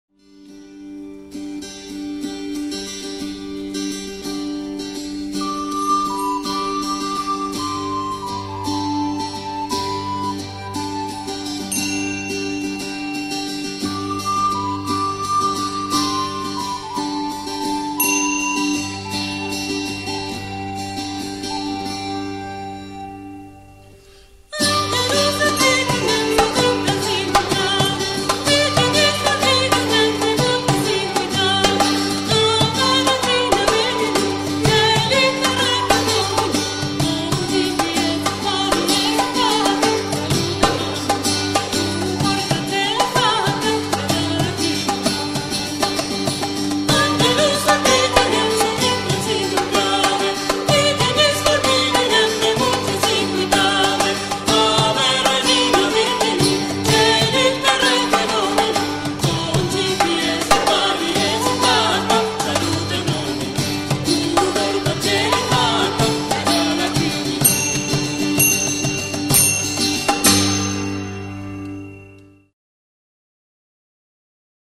Flautas, wisthles
Guitarra, voz
Percusión, voces, accesorios
Gaita gallega, gaita irlandesa
Violín